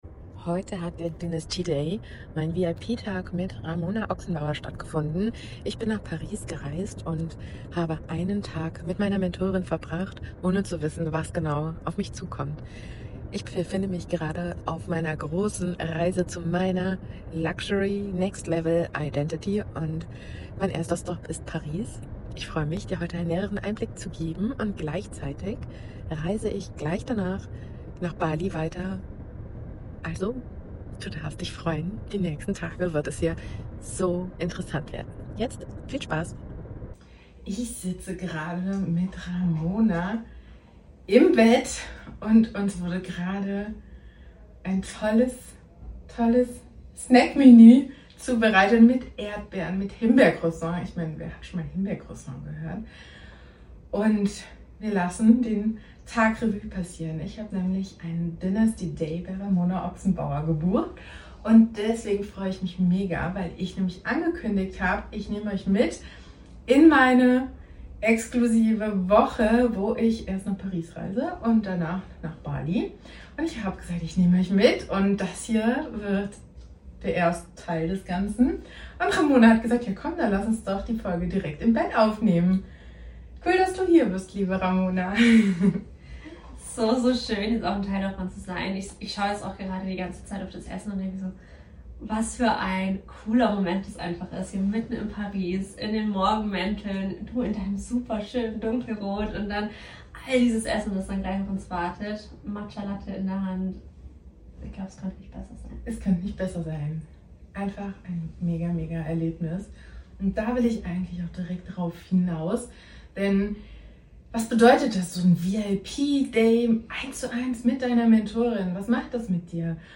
Live aus Paris mein VIP Tag & was jetzt für mein Retreat klar ist ~ Here I Am Podcast